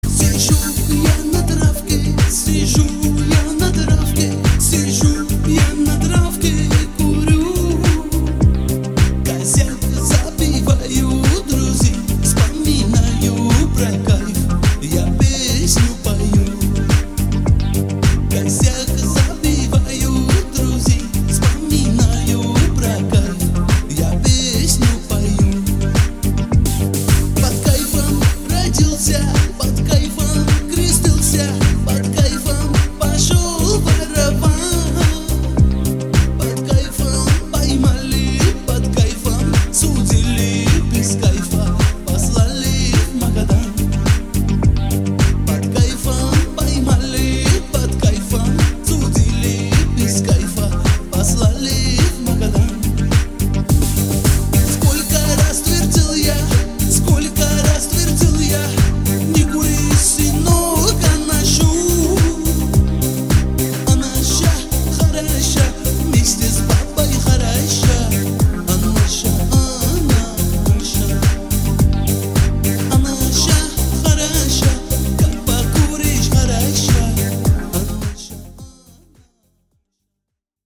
• Качество: 192, Stereo
восточные мотивы
блатные
с акцентом